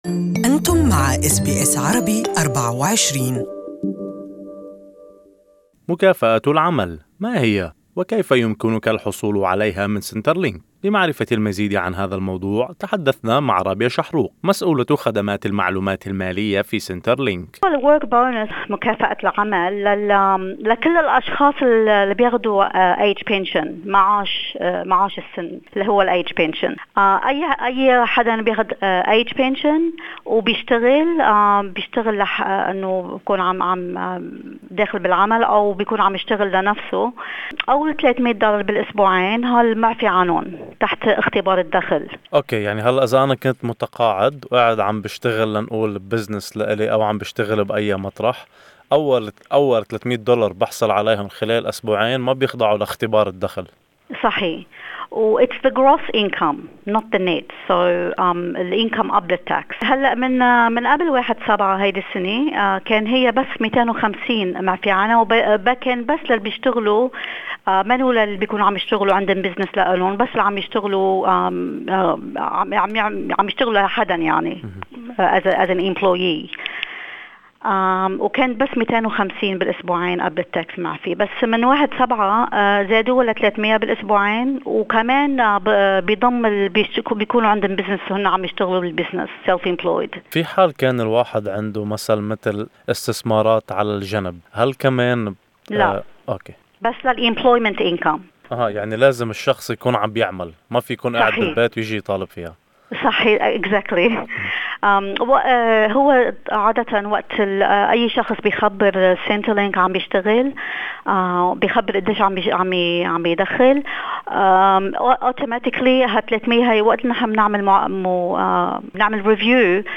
في لقاء صوتي